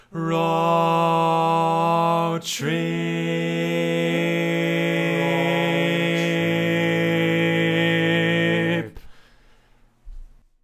Key written in: A Major
How many parts: 4
Type: Barbershop